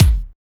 THICK KICK.wav